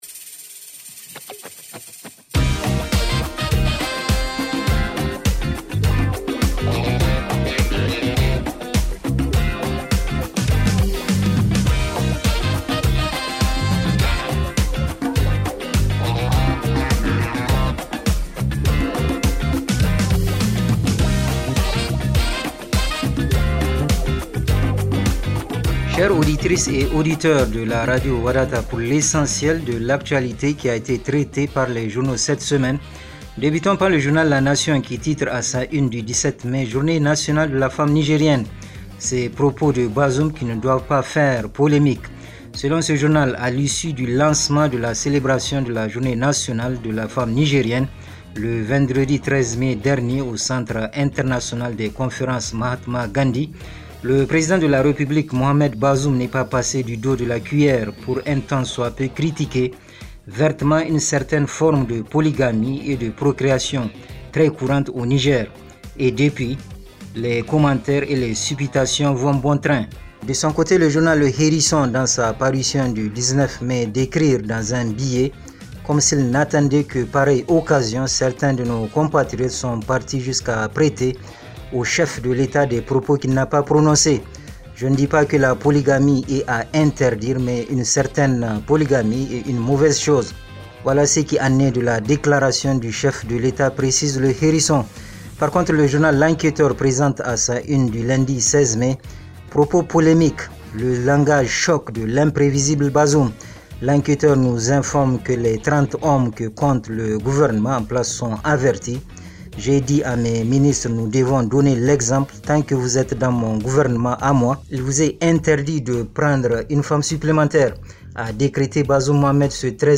Revue de presse en français